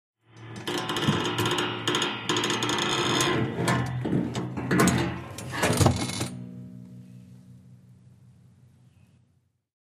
Bed Squeaks
Springs, Bed, Chest, Open, Close Long